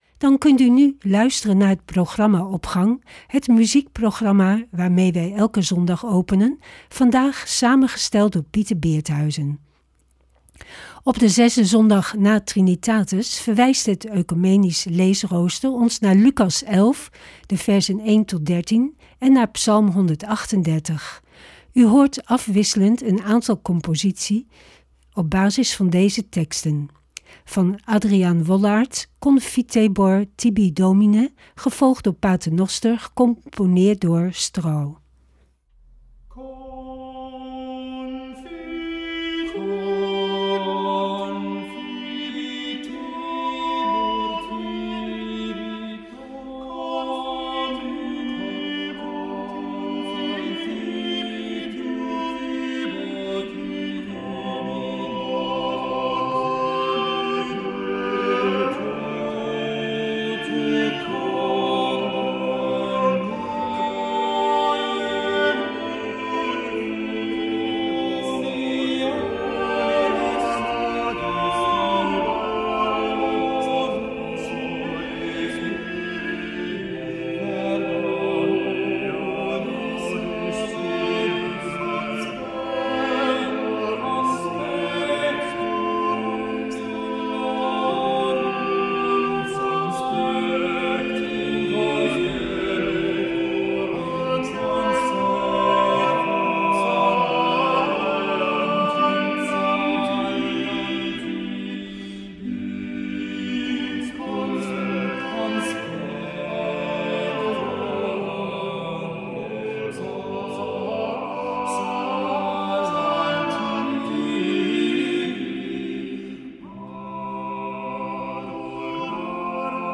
Opening van deze zondag met muziek, rechtstreeks vanuit onze studio.
In deze uitzending van Opgang hoort u afwisselend een aantal composities op basis van deze twee teksten.